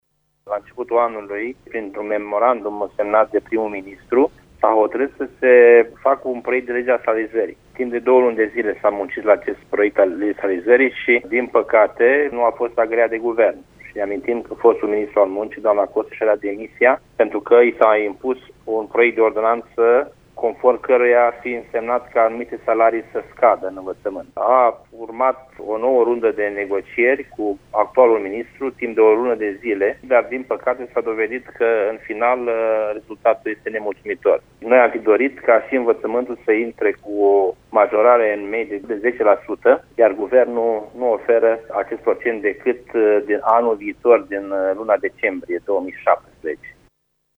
Acţiunea de protest a început la ora 11.00, în faţa Palatului Victoria.